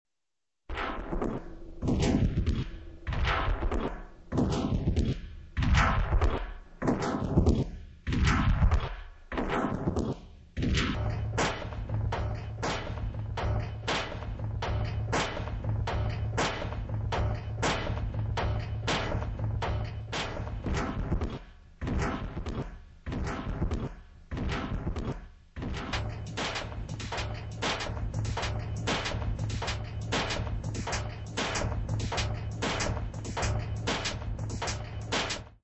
Musik & Elektro-Akustik: Zack Settel
denn in ihr vereinigt sich lebendiger Gesang mit Videokunst und hochmoderner elektronischer Audiotechnik zu einem hinreißenden Gesamtkunstwerk.